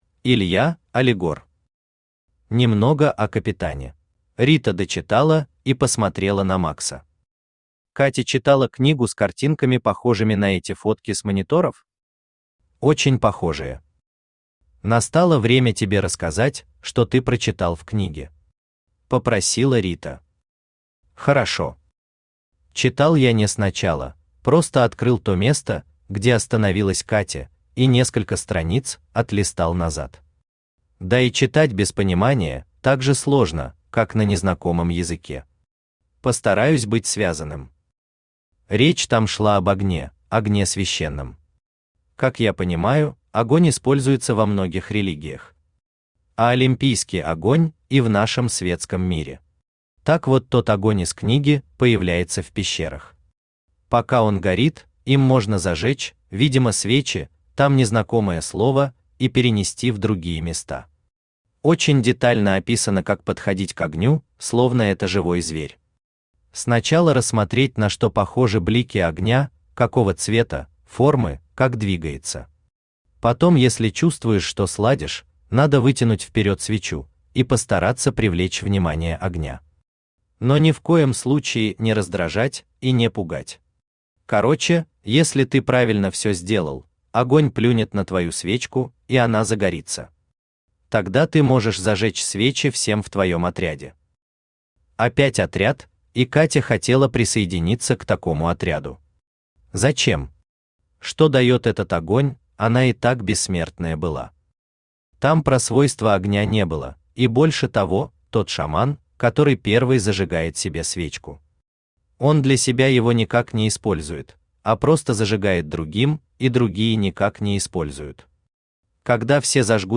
Аудиокнига Немного о Капитане | Библиотека аудиокниг
Aудиокнига Немного о Капитане Автор Илья Алигор Читает аудиокнигу Авточтец ЛитРес.